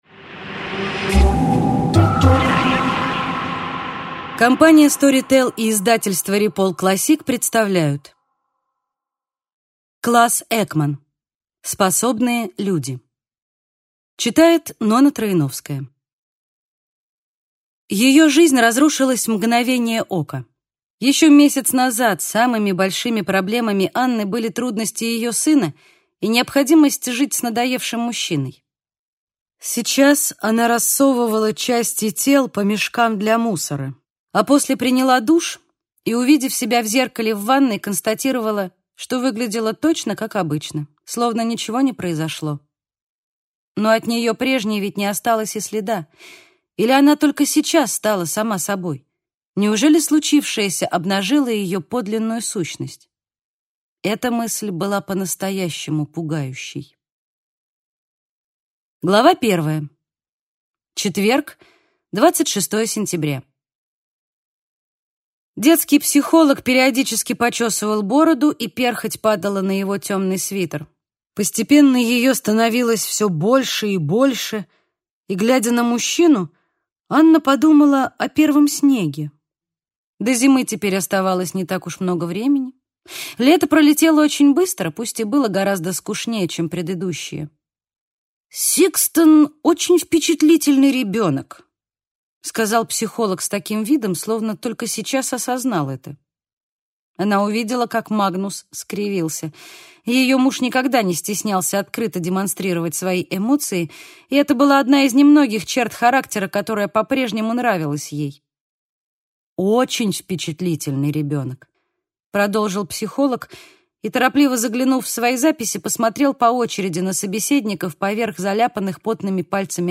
Аудиокнига Способные люди | Библиотека аудиокниг